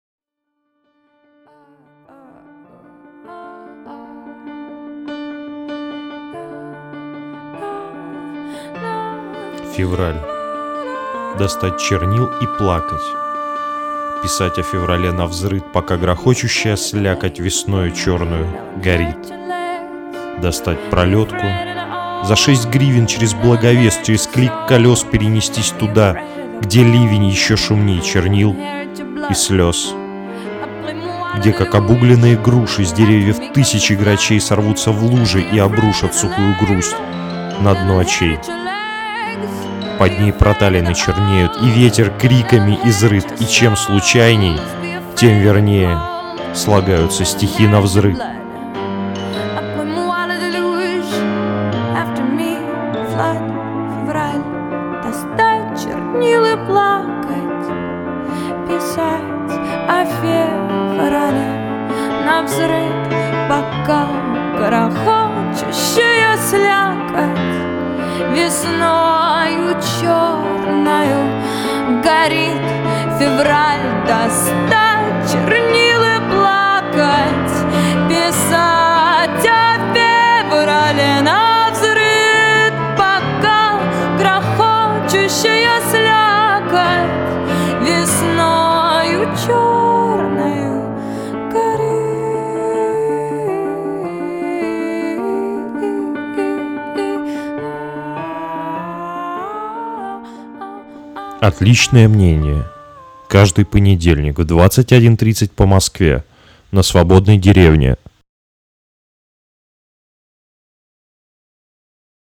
Чтец не очень... не очень с качественным микрофоном :-)